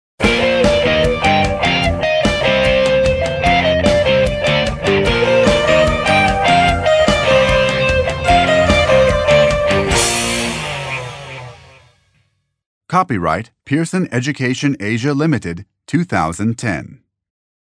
این مجموعه با لهجه ی  امریکن و توسط نویسندگانی چون Beat Eisele, Catherine Yang Eisele, Stephen M. Hanlon, Rebecca York Hanlon به رشته تحریر درآمده است و همچنین دارای سطح بندی از مبتدی تا پیشرفته می باشد و سراسر مملو از داستان های جذاب با کاراکتر های دوست داشتنی می باشد که یادگیری و آموزش زبان انگلیسی را برای کودکان ساده و لذت بخش تر خواهد کرد.